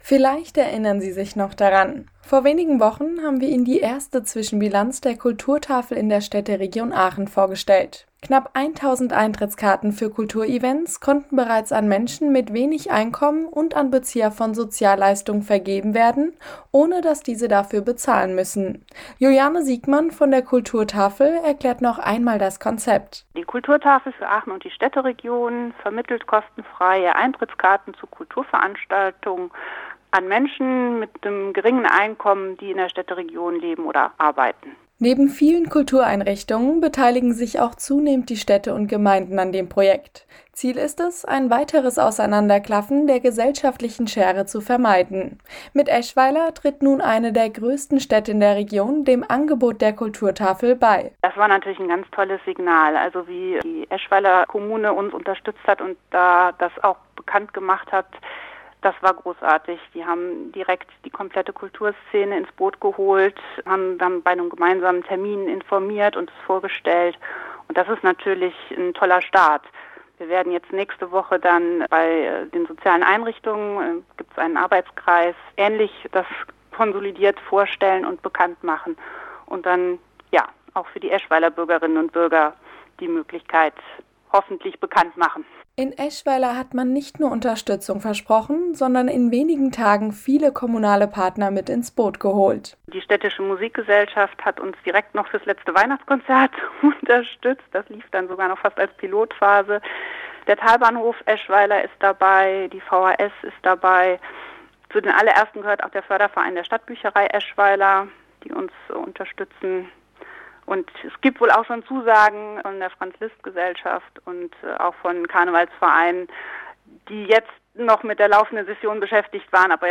5-minütiges Radiointerview  zum aktuellen Stand.